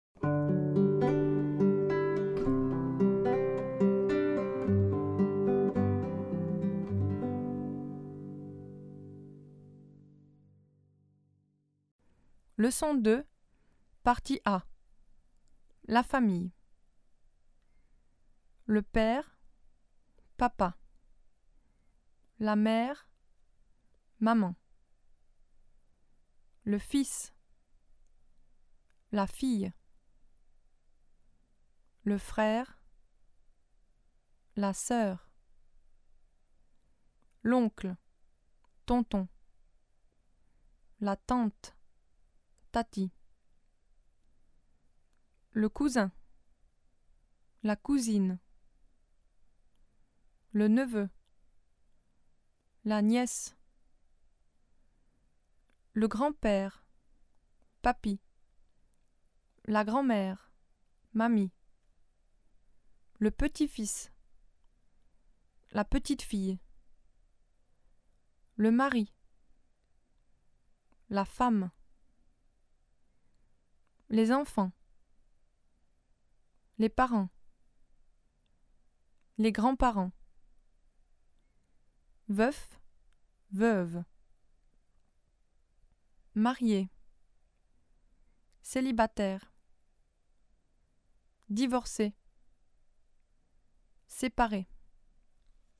famille (prononciation) famille__prononciation_